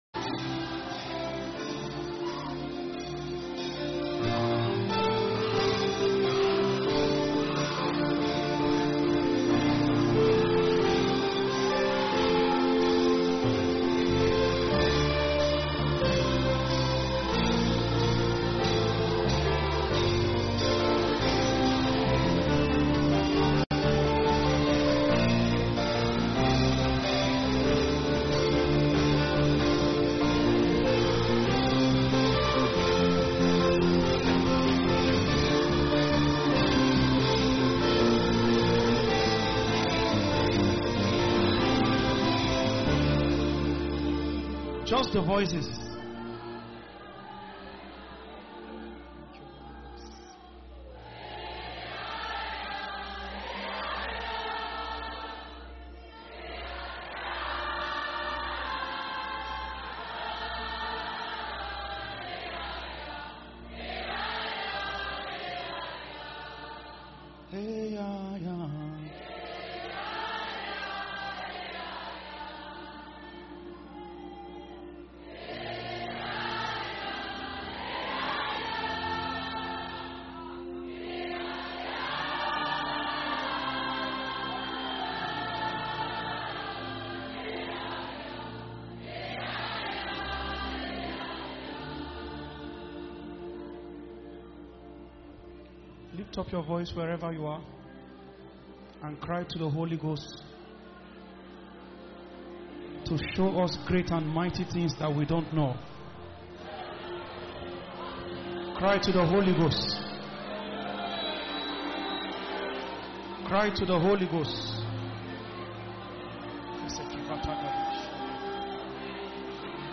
[Sermon]